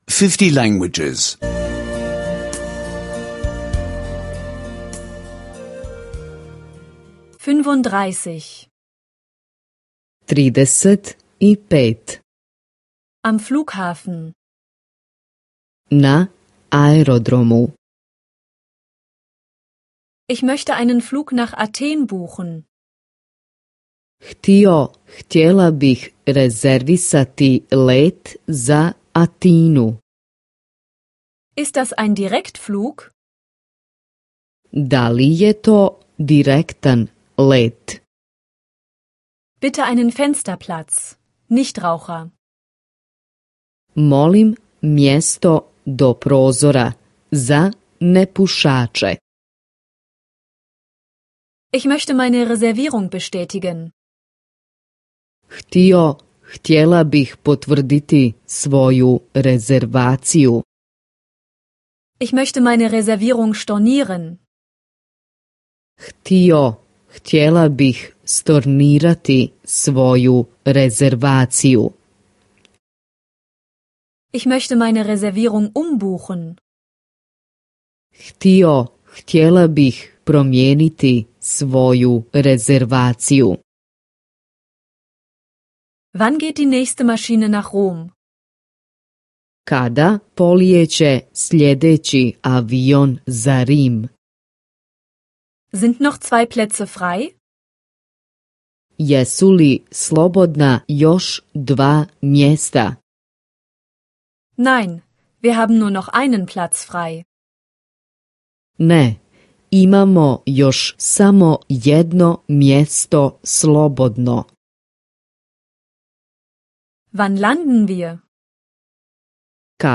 Bosnisch Sprache-Audiokurs (kostenloser Download)